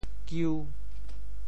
球 部首拼音 部首 王 总笔划 11 部外笔划 7 普通话 qiú 潮州发音 潮州 giu5 文 中文解释 球〈名〉 (形声。